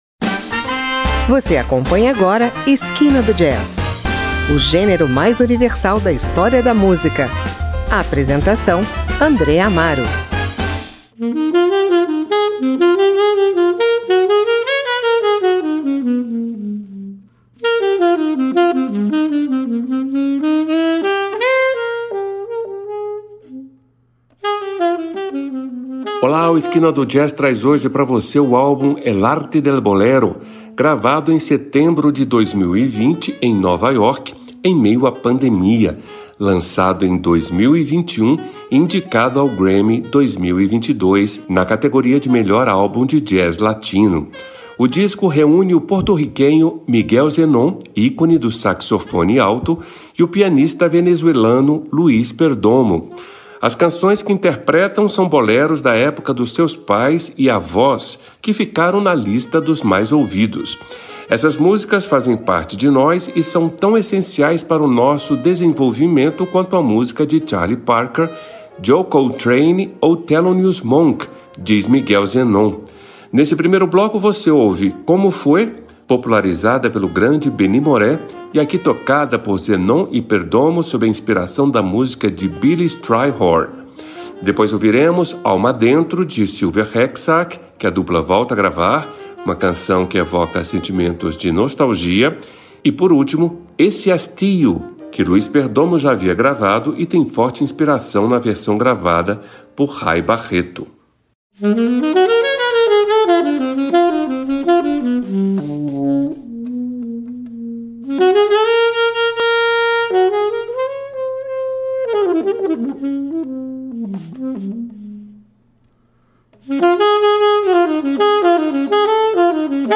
gravado em setembro de 2020 na The Jazz Gallery em Nova York
ícone do saxofone alto